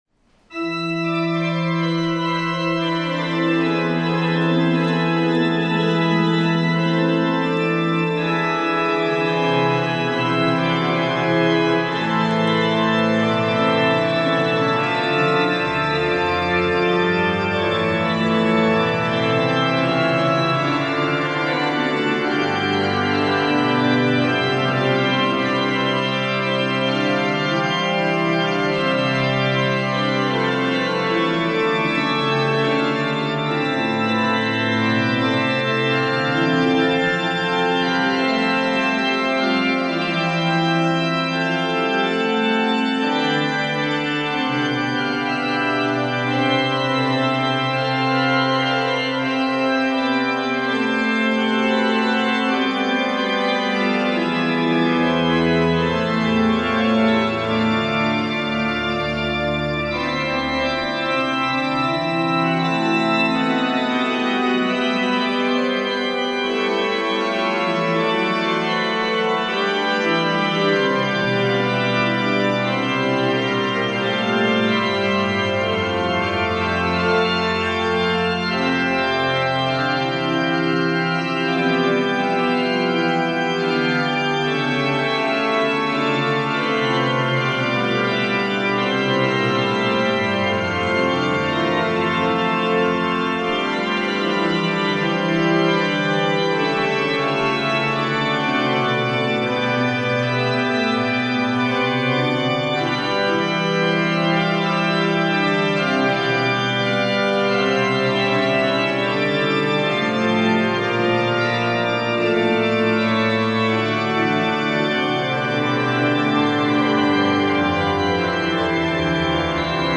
de l'église prieurale Saint-Pierre et Saint-Paul
Les extraits montrent quelques échantillons des sonorités particulières de l'orgue.
En taille à quatre
Fugue à cinq